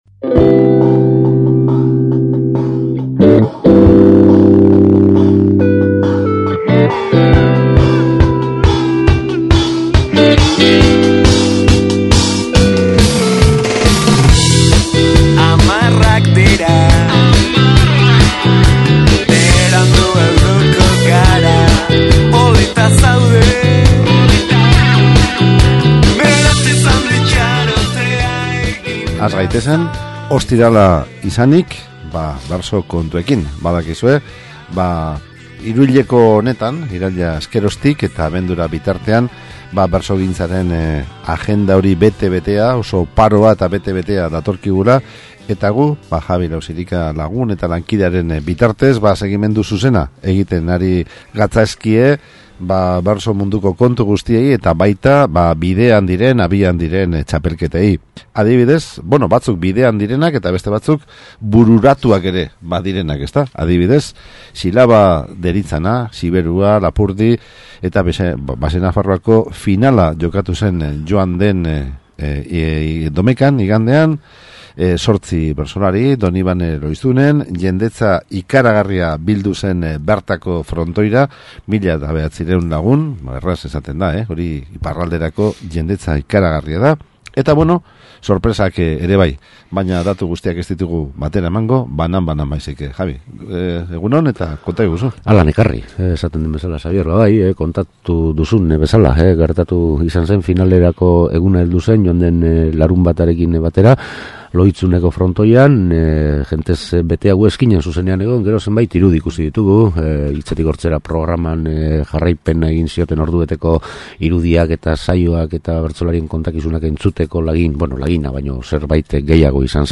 solasaldia
Egun horretako foto orokorra eta bertsolariek kantatu zuten azken agurra entzungai.